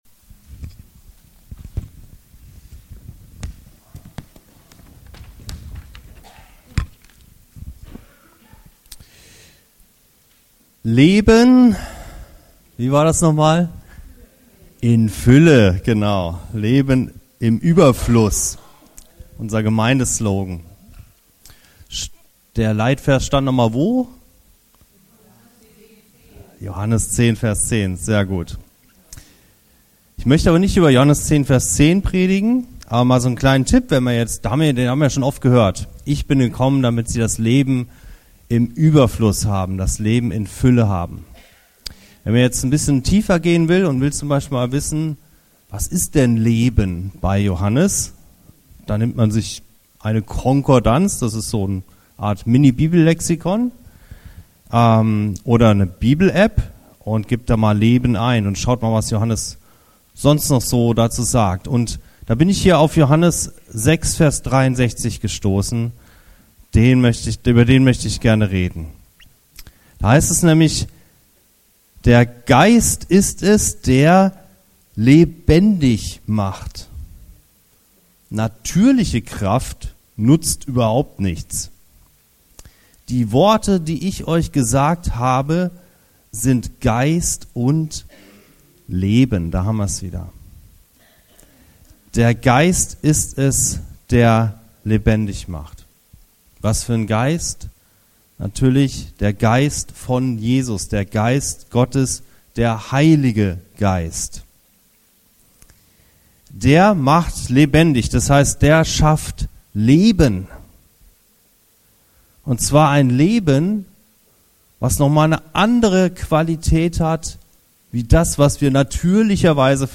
Eine andere Art zu Leben - Leben im Überfluss ~ Anskar-Kirche Hamburg- Predigten Podcast